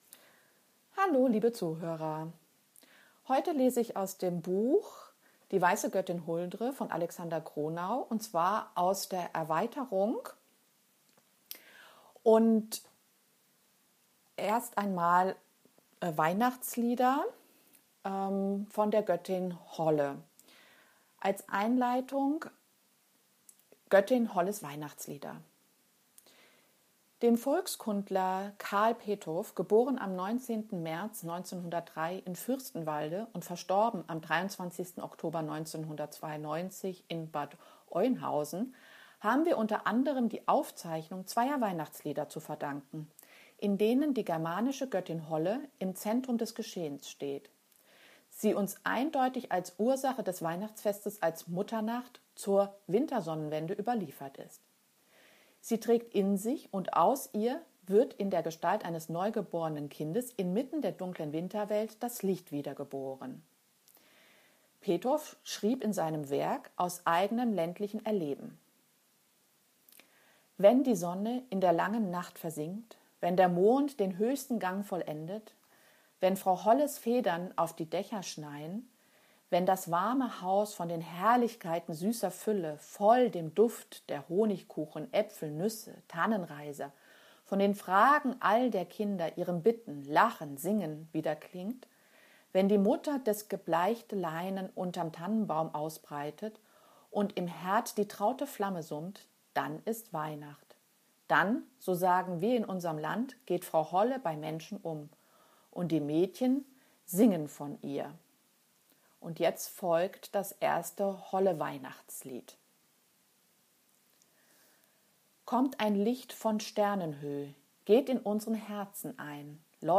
Weitere Lesungen